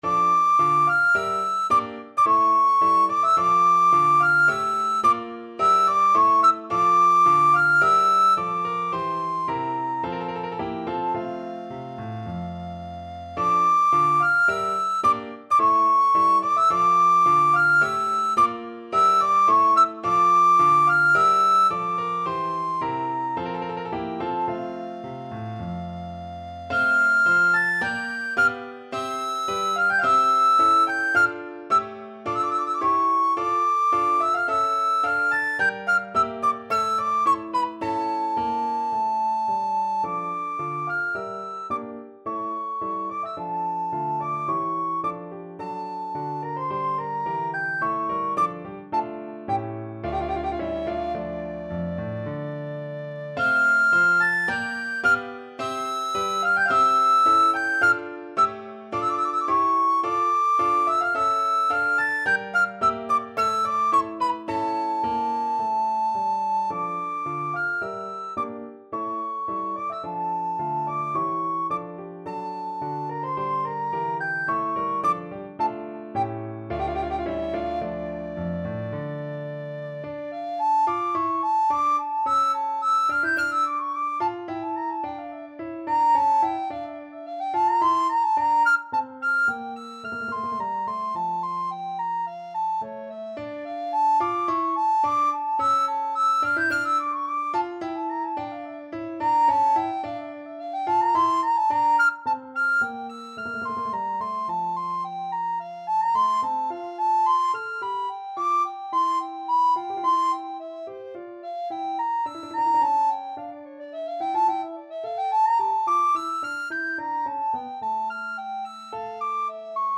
Free Sheet music for Soprano (Descant) Recorder
Recorder
D minor (Sounding Pitch) (View more D minor Music for Recorder )
3/4 (View more 3/4 Music)
~ = 54 Moderato
Classical (View more Classical Recorder Music)